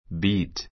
beat 中 bíːt ビ ー ト 動詞 三単現 beats bíːts ビ ー ツ 過去形 beat ⦣ 原形と同じ形であることに注意.